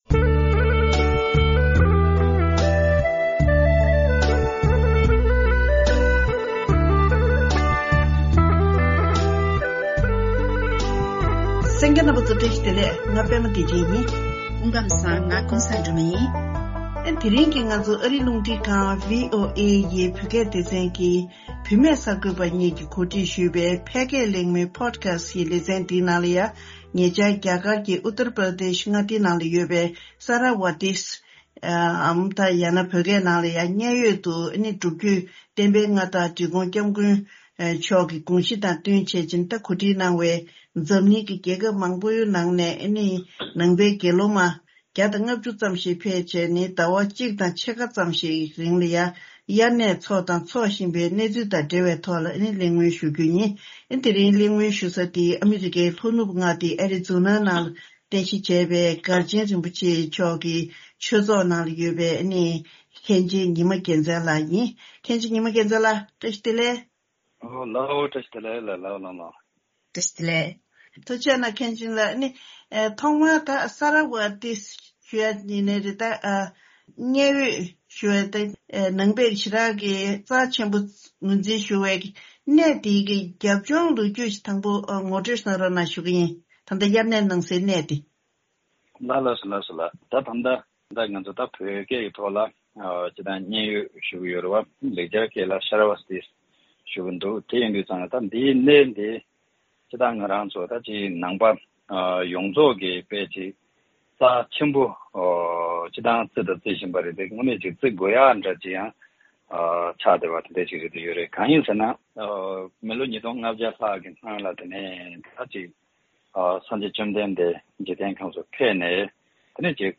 ༄༅། སྔོན་བདག་ཅག་གི་སྟོན་པ་སངས་རྒྱས་ཤཱཀྱ་ཐུབ་པ་དབྱར་གནས་ཐེངས་ཉི་ཤུ་རྩ་ལྔ་བཞུགས་གནས་མཛད་ས་རྒྱ་གར་གྱི་གནས་ཆེན་མཉན་ཡོད་ཀྱི་ལོ་རྒྱུས་དང་། དེ་བཞིན་འབྲི་གུང་༧སྐྱབས་མགོན་མཆོག་གིས་མཐུན་འགྱུར་འོག་མཉན་ཡོད་དུ་དགེ་སློང་མ༡༥༠ཙམ་ཞིག་གིས་དབྱར་གནས་ལ་བཞུག་བཞིན་ཡོད་པའི་སྐོར་ལ་གླེང་མོལ་ཞུས་པ་ཞིག་གསན་རོགས་གནང་།